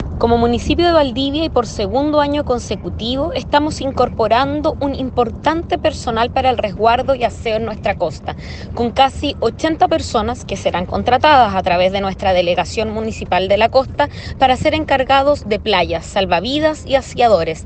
En el caso de Valdivia, la alcaldesa, Carla Amtmann, detalló que serán contratadas cerca de 80 personas para labores de salvavidas y aseo.